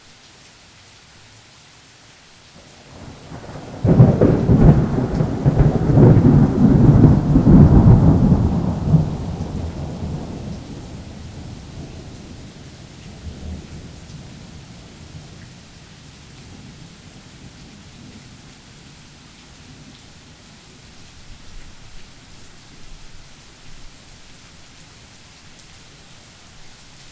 enviro_thunder_3.wav